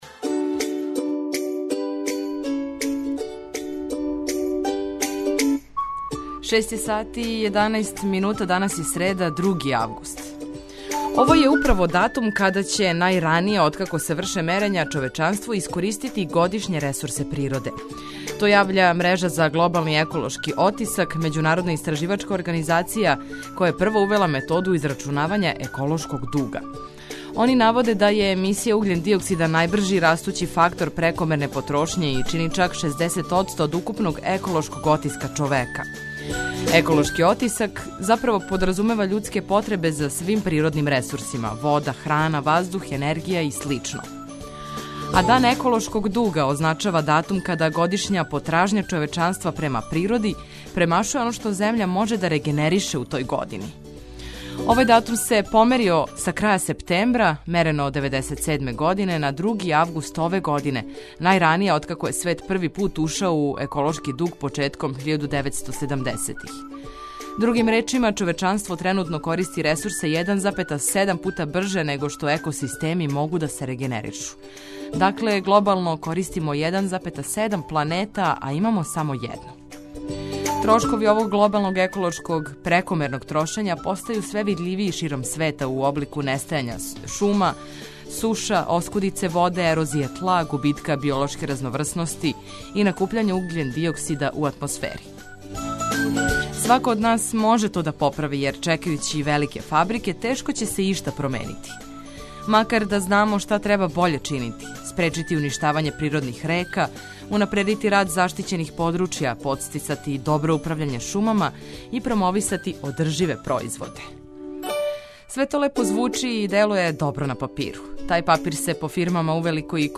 Прве сате јутра испунили смо музиком за разбуђивање и информацијама које су важне.